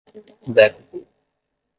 Pronunciado como (IPA) /ˈdɛ.ku.plu/